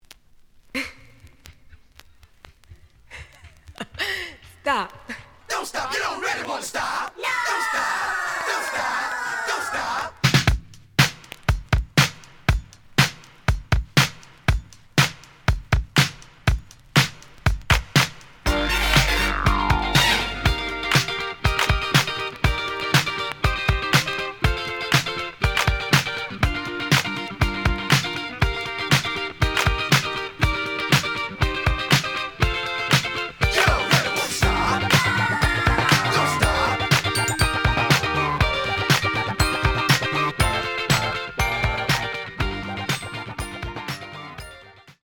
試聴は実際のレコードから録音しています。
The audio sample is recorded from the actual item.
●Genre: Funk, 80's / 90's Funk